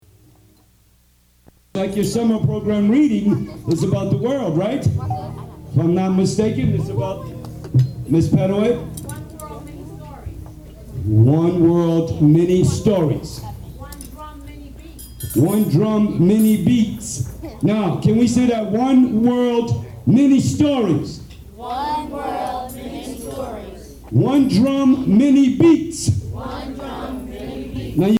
Live recordings from Stetson!